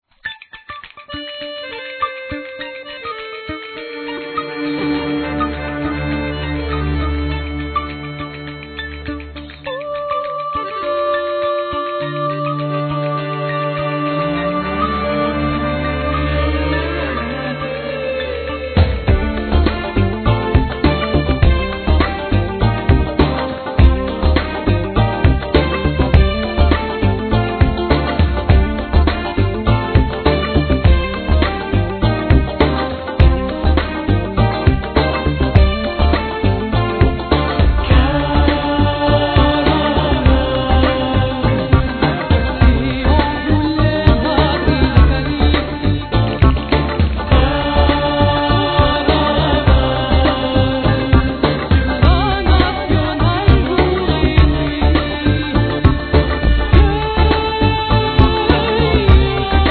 Vocals,Bendir,Spanish claps
Cello,Violin